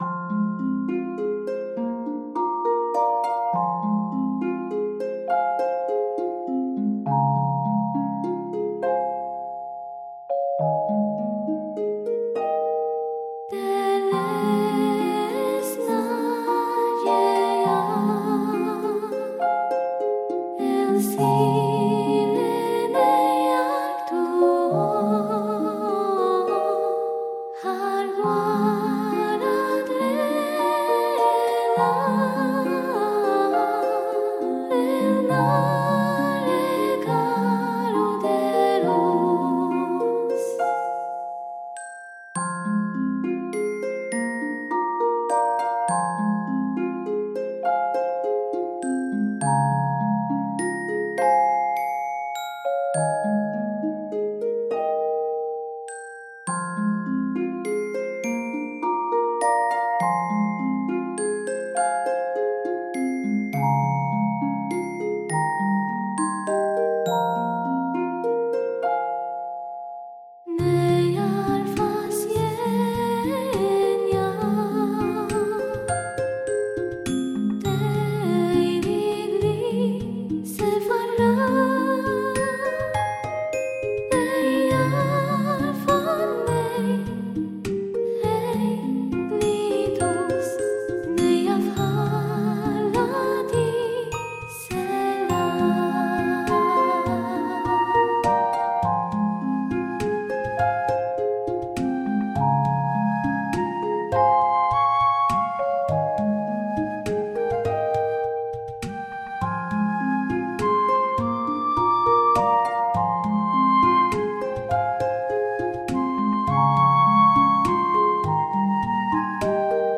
ファンタジー系フリーBGM｜ゲーム・動画・TRPGなどに！